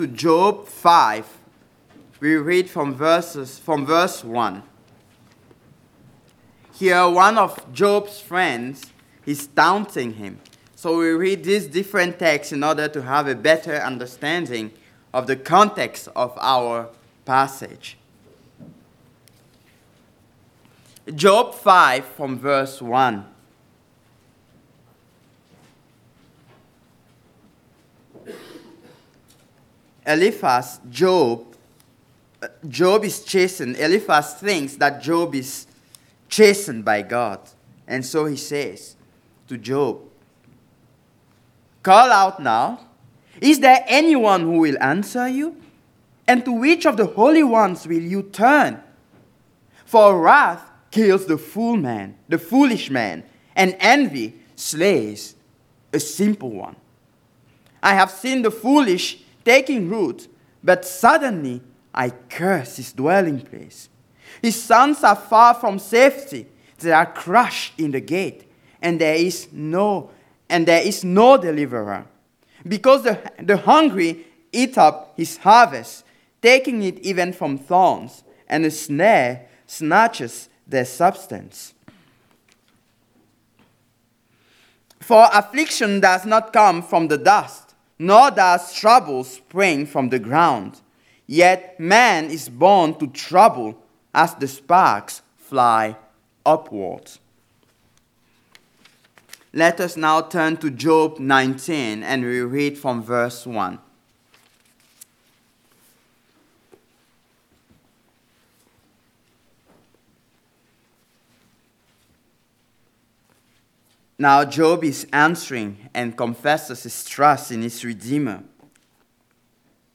Passage: Job 19:25-27 Service Type: Sunday Morning